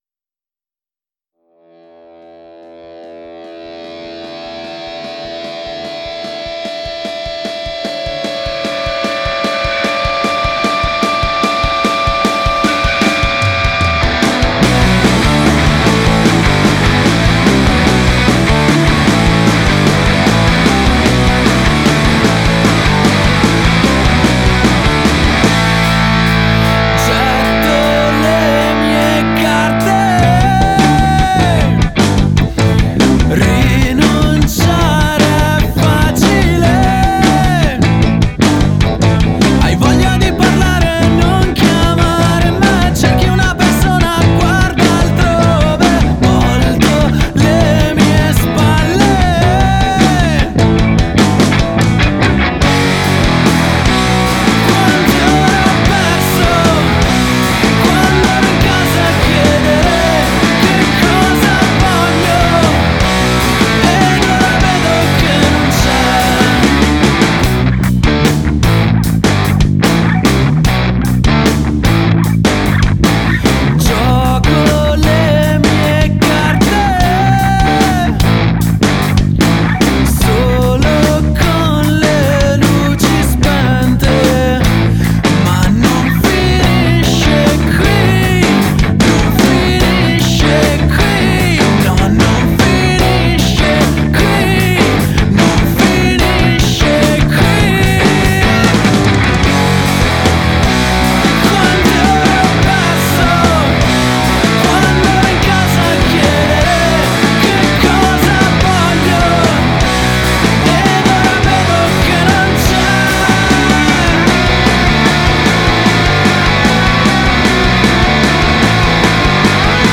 Luogo esecuzionePeak Studio
GenereRock